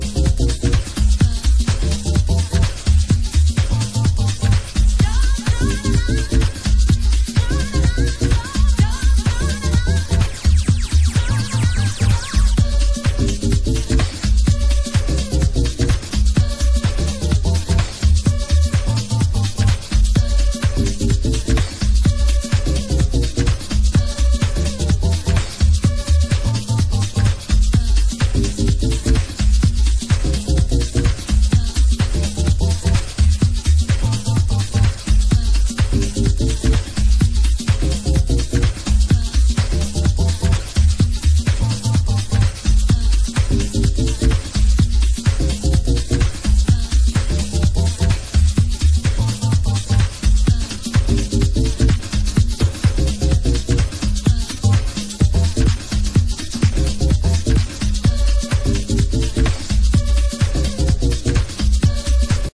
A mega thick house production for primetime club use.